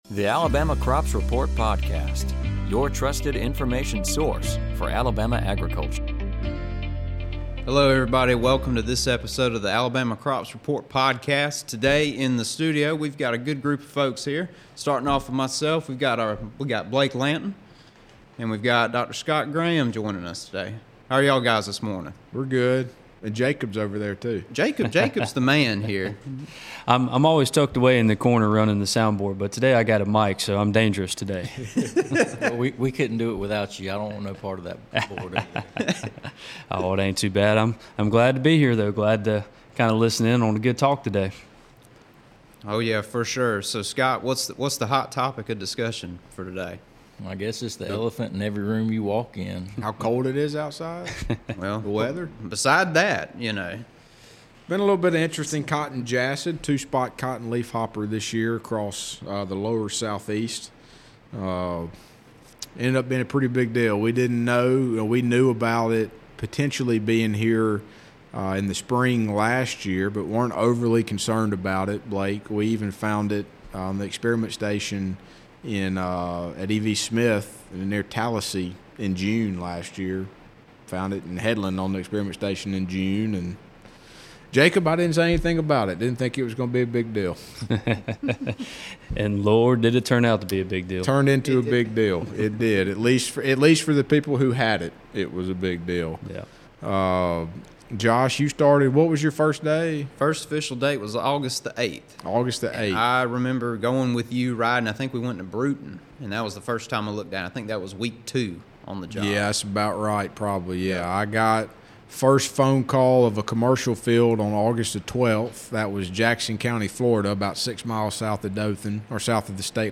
Episodes will be 10 to 15 minutes long in conversational form.